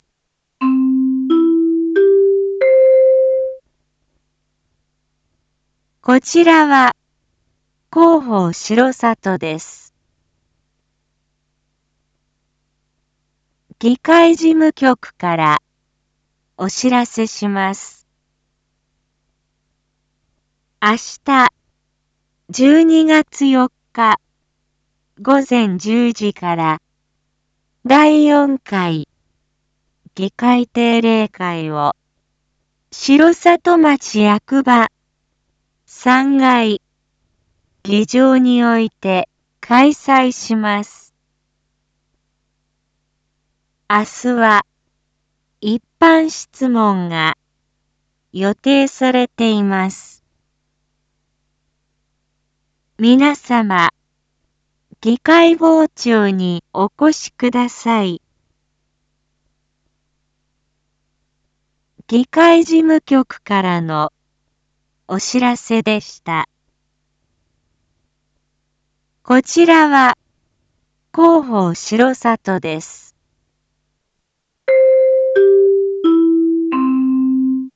Back Home 一般放送情報 音声放送 再生 一般放送情報 登録日時：2025-12-03 19:01:19 タイトル：R7.12.4 第４回議会定例会⑤ インフォメーション：こちらは広報しろさとです。